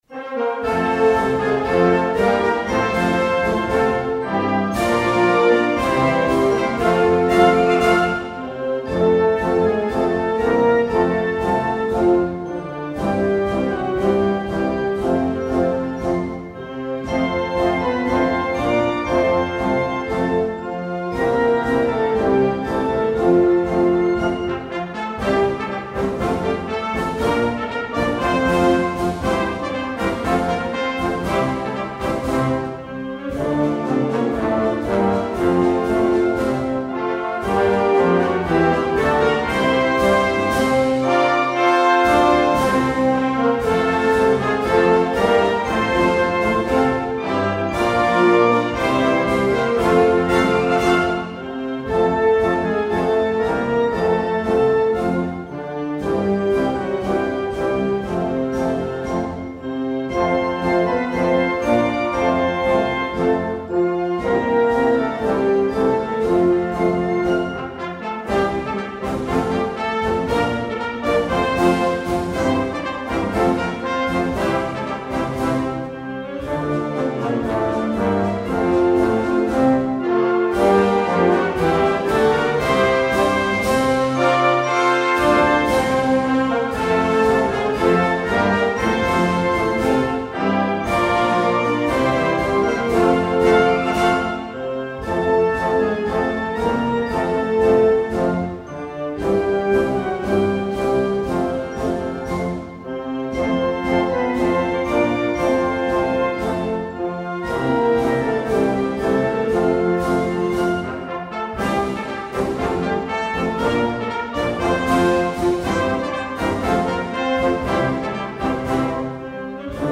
真岡中学校校歌 校歌（吹奏楽）.mp3